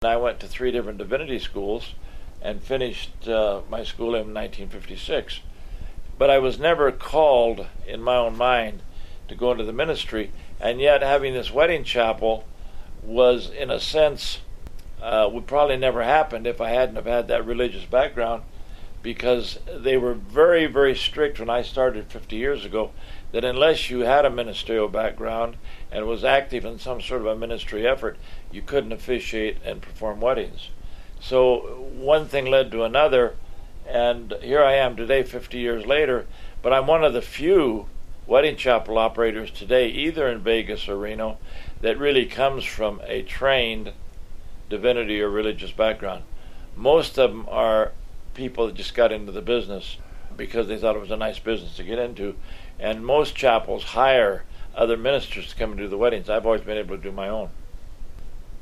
Interviewed in 2012